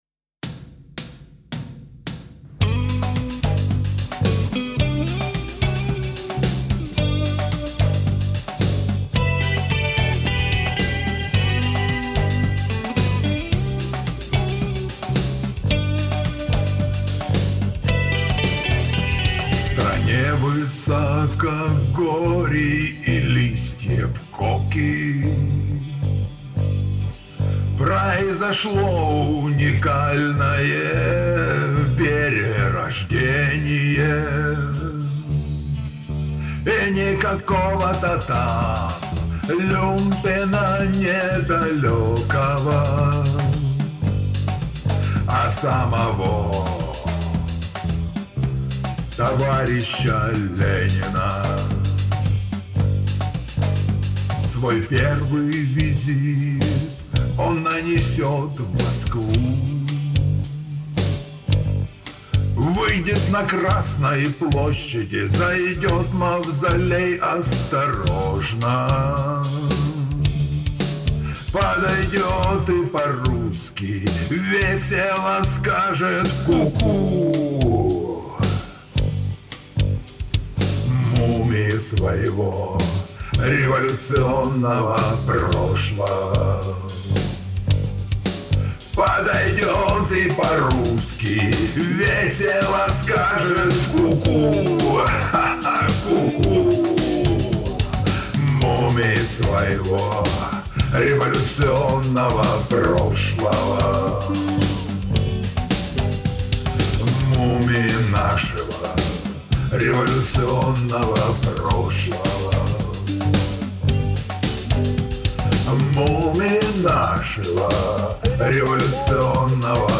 Мы с другом даже песенку быстро сочинили и записали. Извините за частоты...
И не важно, что кое-где в ноты не попадал.
здесь не до попаданий в ноты  :-)